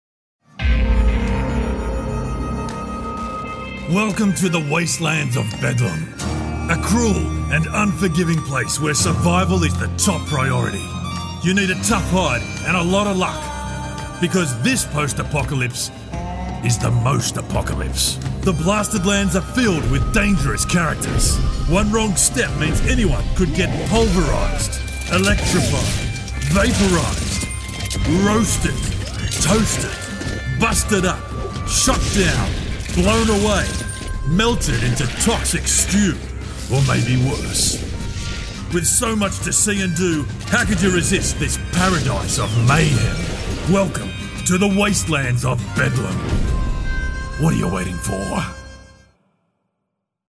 Inglês (australiano)
Animação
A mistura perfeita de leitura casual, um ar de autoridade e um sorriso que você pode ouvir?
Rode NT-1, microfones Sennheiser 416
BarítonoGravesContraltoProfundoBaixo